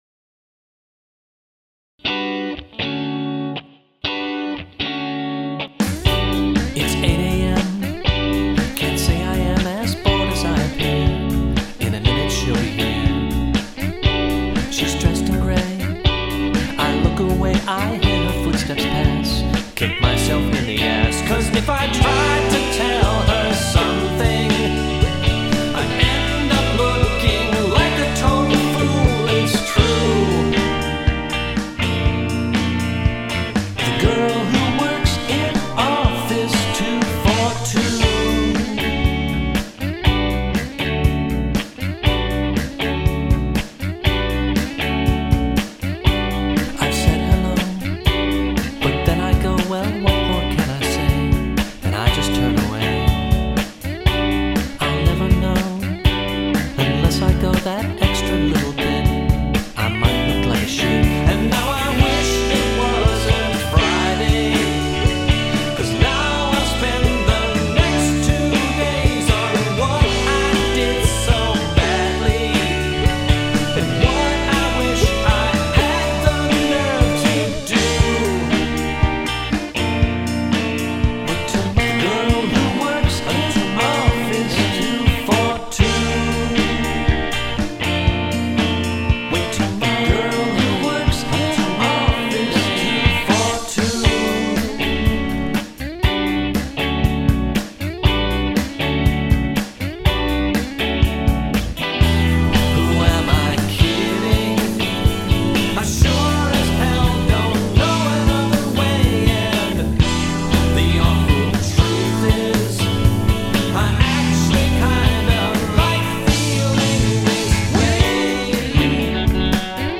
I enjoy the lyrics, but would lose the cheesy synth in the background.
He accents the cymbals just enough to add the right amount of dynamics without getting in the way of the song.
I’m usually fond of guitar breaks that mimic the vocal line, but this time around I’m not digging it.
I’m IN LOVE with the synth in the pre-chorus and think it should be mixed higher.